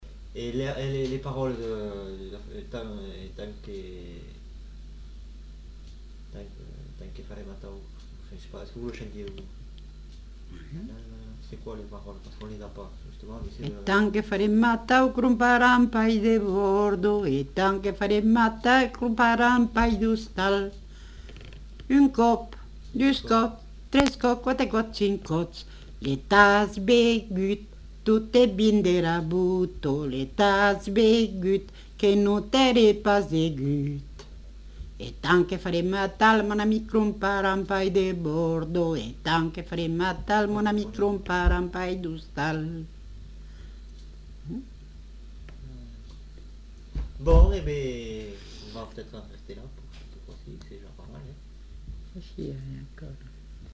Lieu : Montauban-de-Luchon
Genre : chant
Effectif : 1
Type de voix : voix de femme
Production du son : chanté
Description de l'item : fragment ; 1 c. ; refr.
Classification : danses